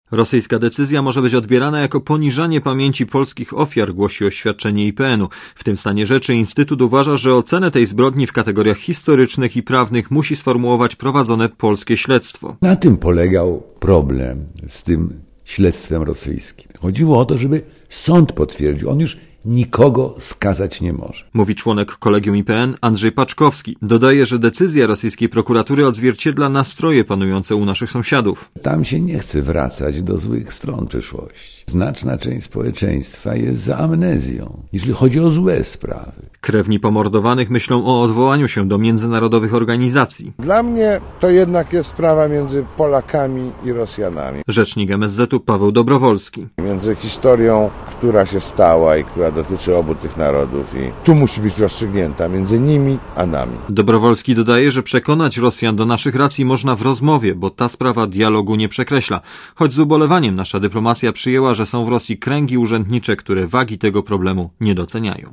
Relacja reportera Radia ZET